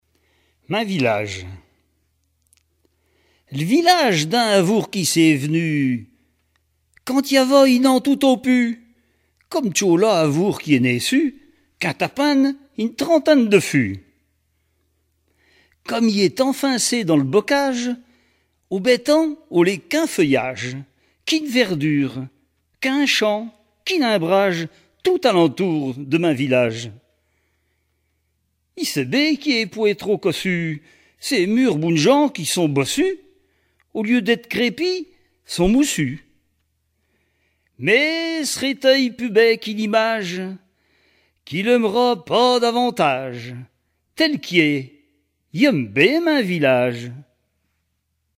Patois local
Genre poésie